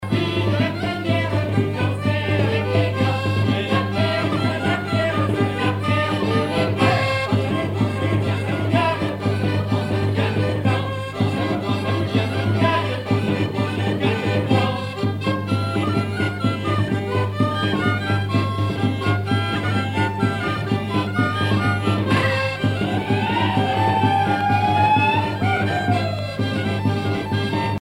Chants brefs - A danser
danse : gigouillette
Pièce musicale éditée